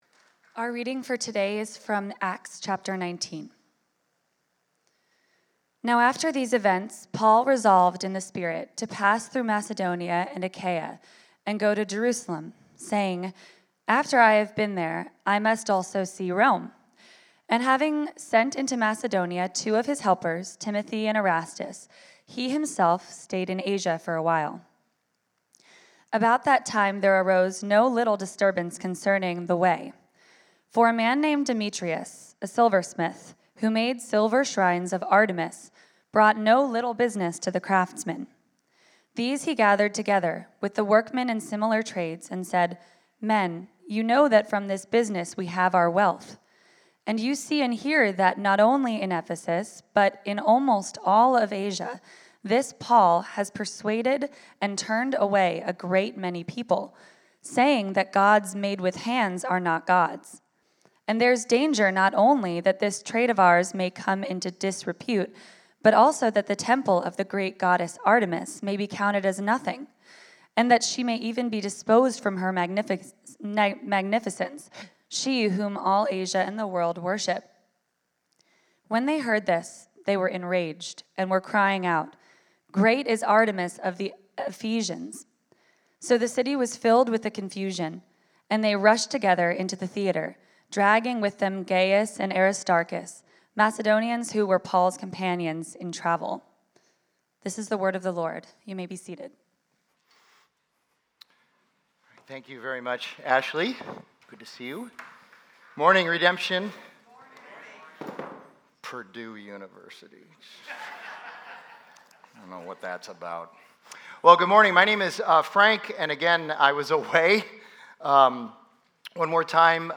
Acts: When the Gospel Changes Culture from Redemption Arcadia Sermons.